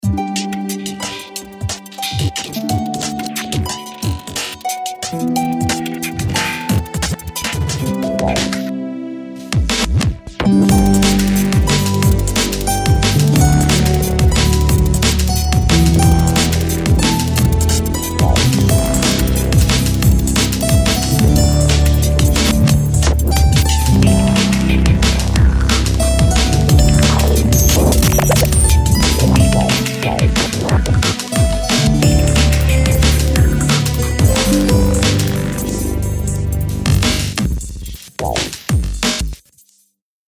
Style: Renaissance Drum & Bass
- Energetic, Full On...